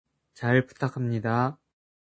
잘 부탁합니다 [チャル プタカㇺニダ]